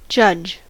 judge: Wikimedia Commons US English Pronunciations
En-us-judge.WAV